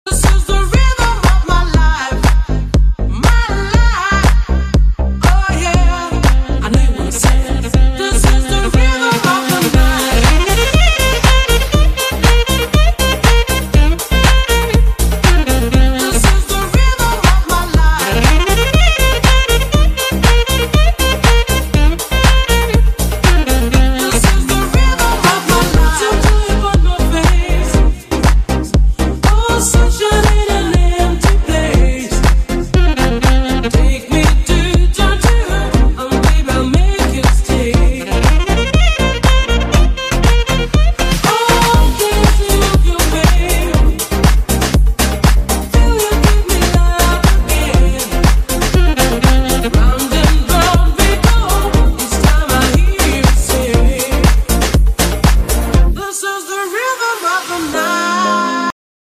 • Качество: 128, Stereo
dance
90-е